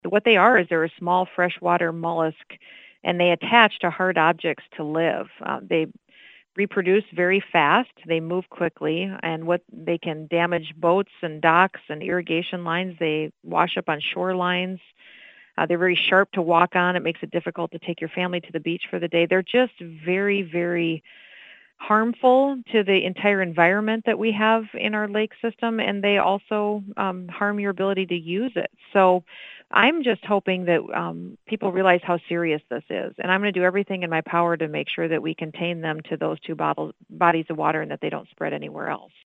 Gov. Kristi Noem says the small invasive mussels create huge problems for both fish and humans.